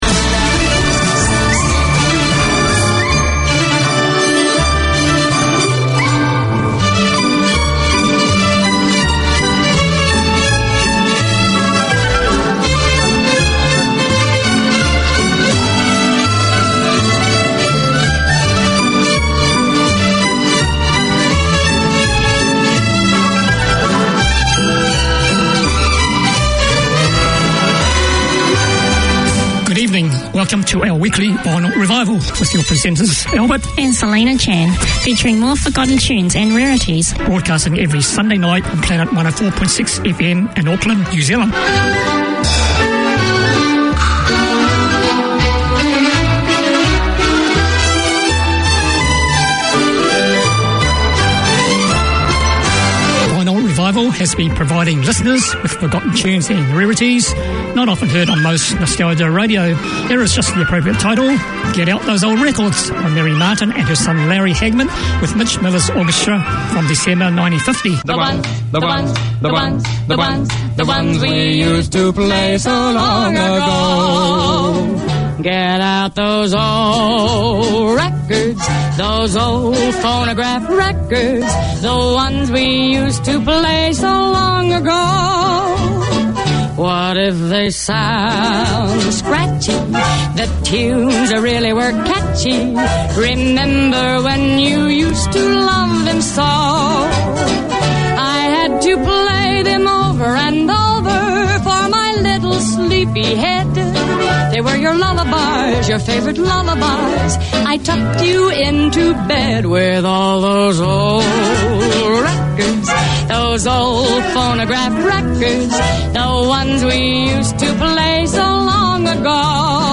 Listen for the best of the 30's,40's 50's,60's including hits and rarities.